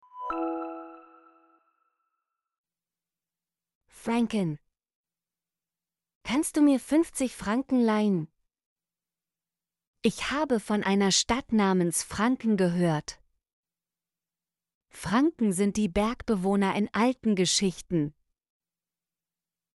franken - Example Sentences & Pronunciation, German Frequency List